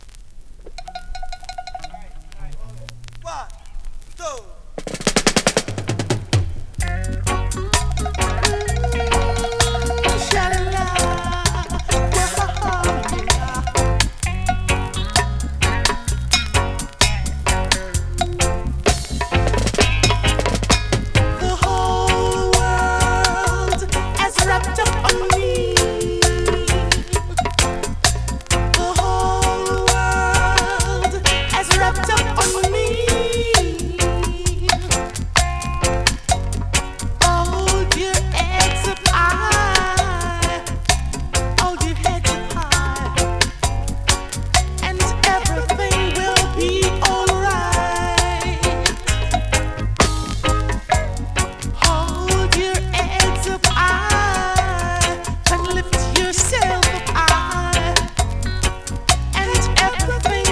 コンディションVG++(少しノイズ)
スリキズ、ノイズ比較的少なめで